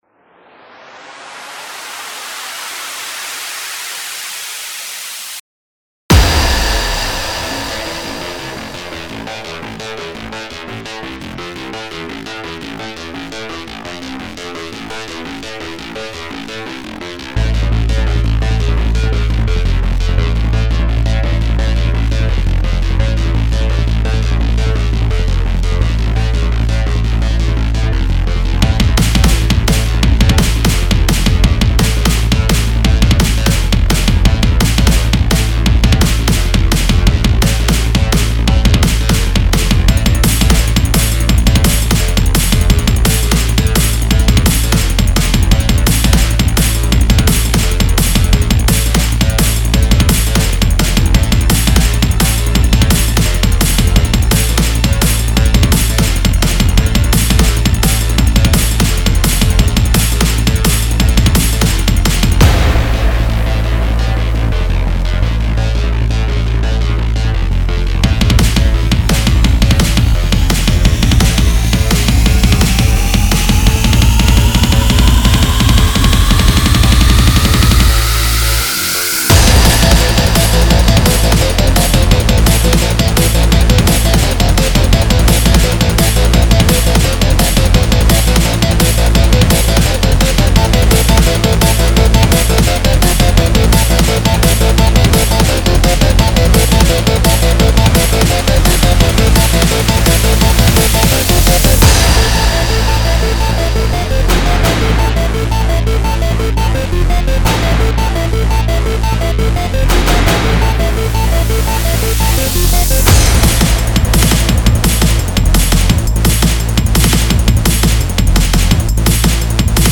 old (metal-ish) breakbeat song (2020)
breakbeat
the song is of course basic and quite repetitive
130 bpm